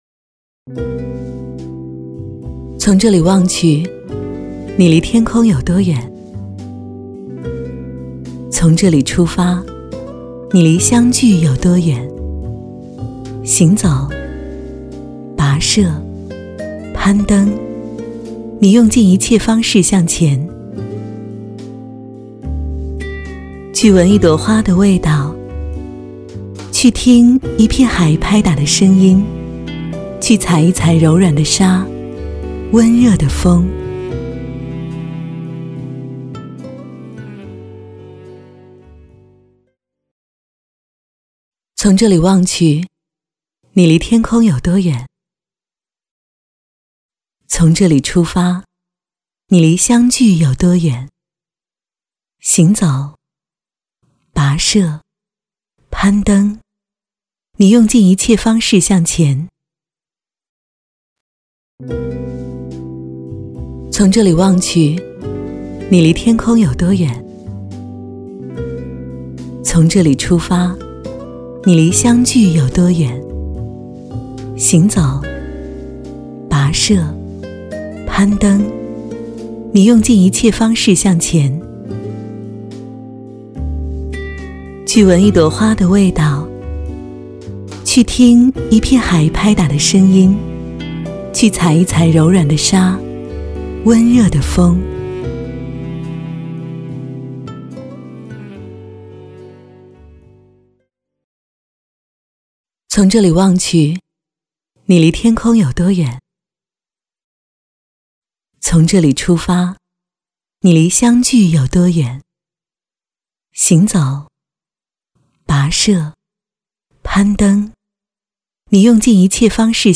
国语青年积极向上 、时尚活力 、神秘性感 、调性走心 、亲切甜美 、女广告 、500元/条女S143 国语 女声 广告-牛栏山-活力 积极向上|时尚活力|神秘性感|调性走心|亲切甜美